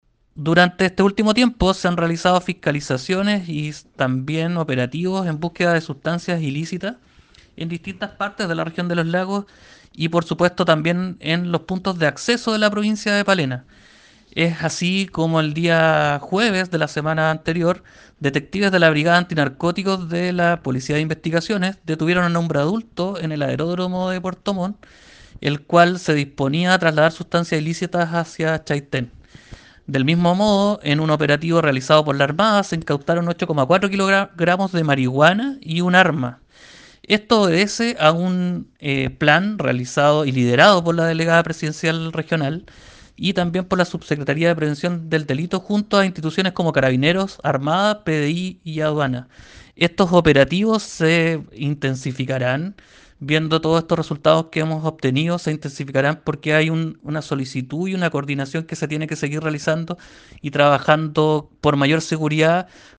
Desde la delegación presidencial provincial de Palena, el delegado Luis Montaña, destacó la labor de prevención que se realiza, para la seguridad de toda la comunidad.
09-DELEGADO-PALENA-REDADAS-ANTIDROGA.mp3